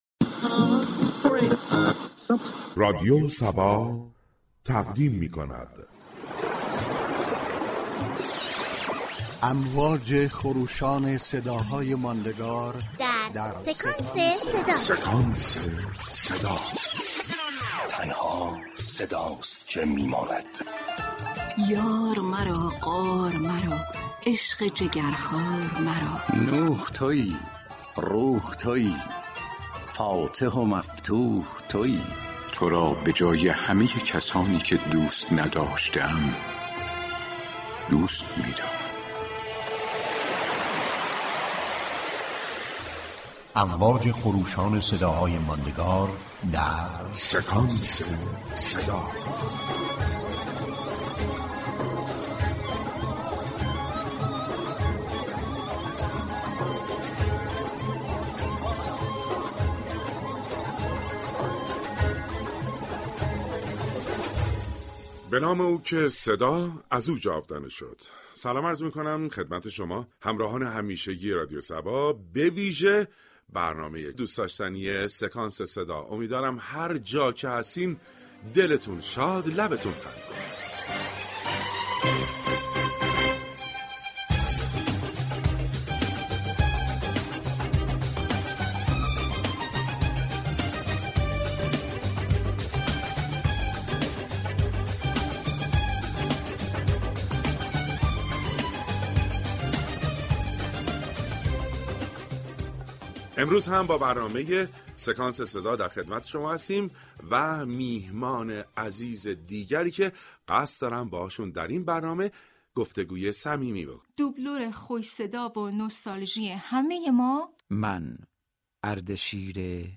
در ادامه بخشی از این برنامه را می شنویم